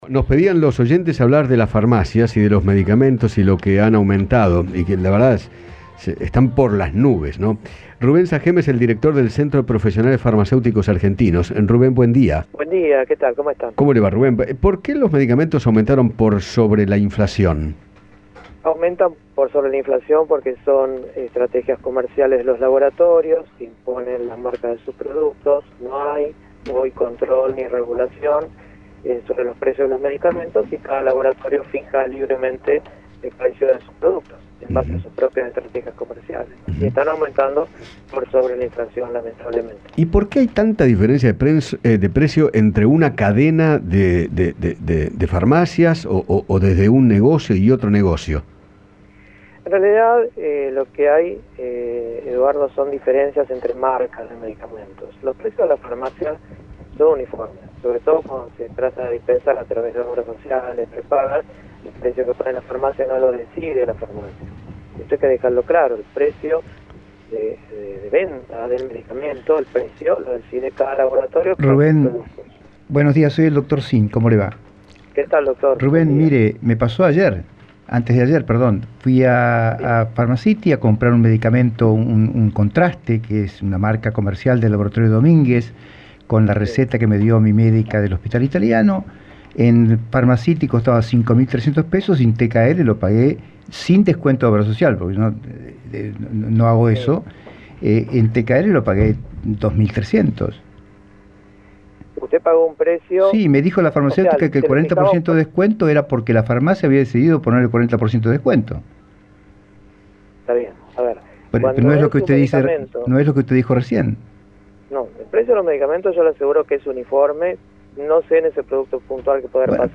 conversó con Eduardo Feinmann acerca del aumento de los precios de los medicamentos y recomendó que el público recorra las farmacias y consulte precios.